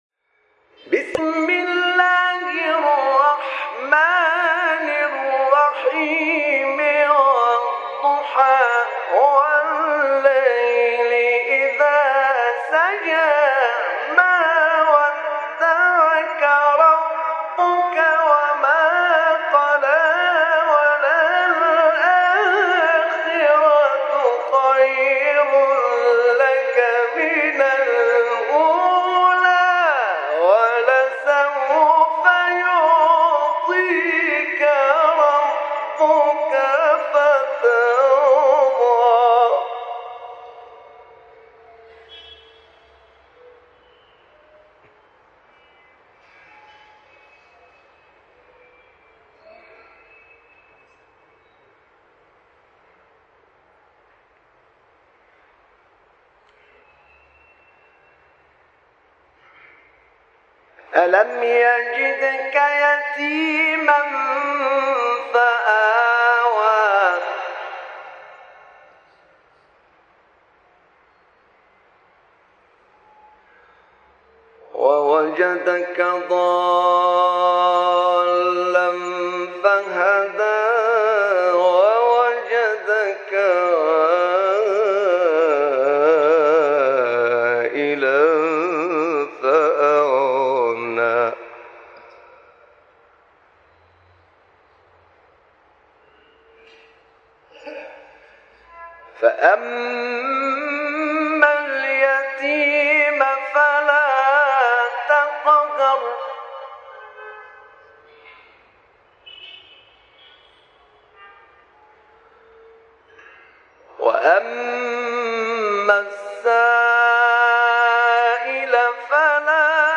تلاوت سوره ضحی انشراح استاد نعینع | نغمات قرآن | دانلود تلاوت قرآن